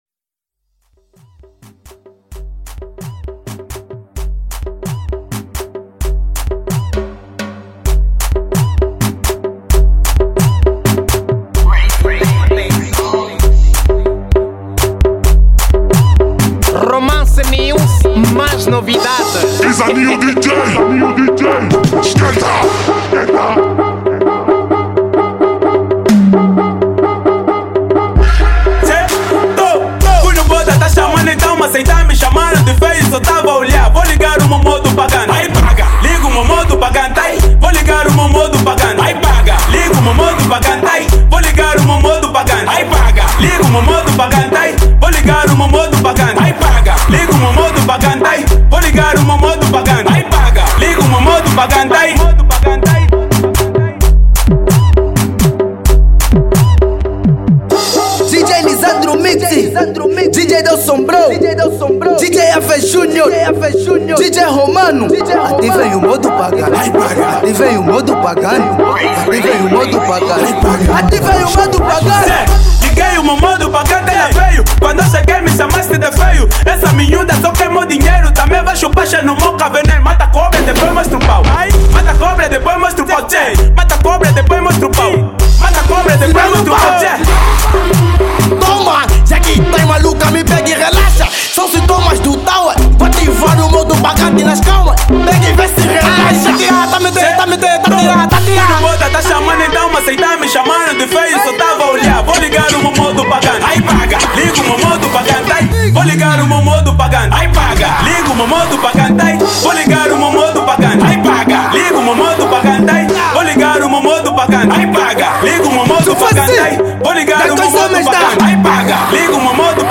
Estilo: Afro House